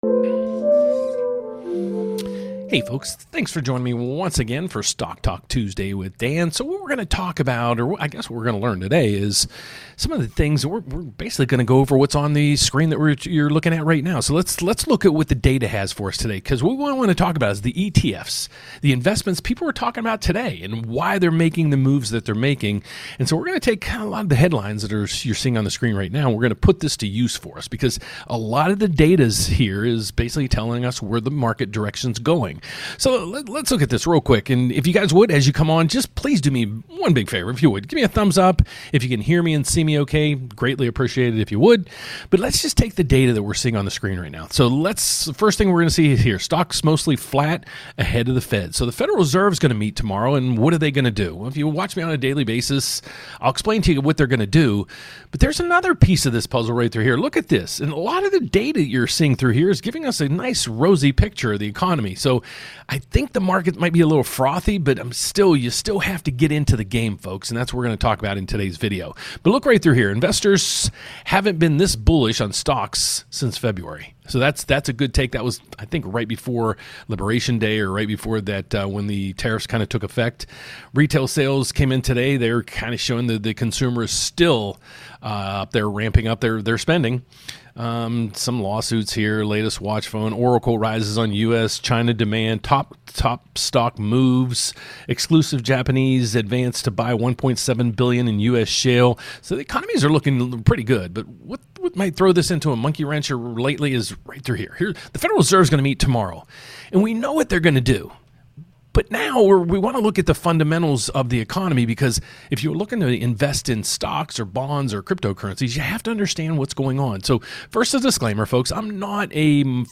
Whether you’re a seasoned investor or just starting your portfolio, this live stream gives you the insights you need to stay ahead of the market.